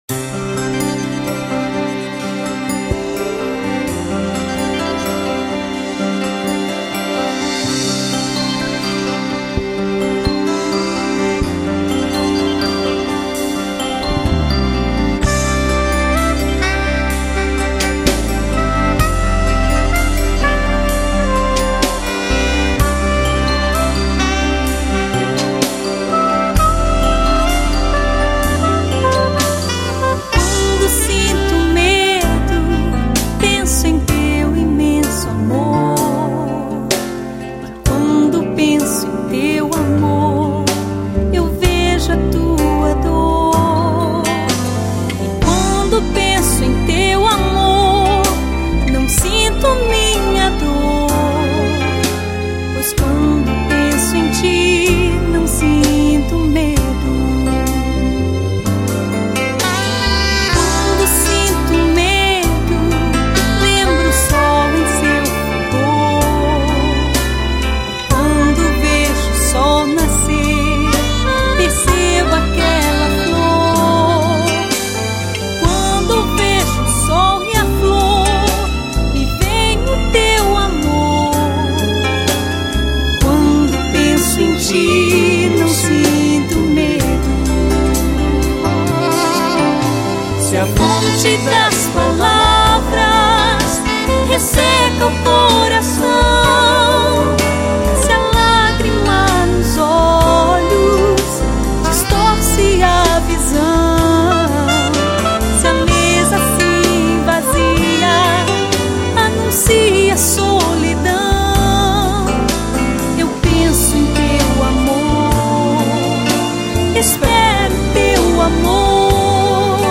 Saxofone Soprano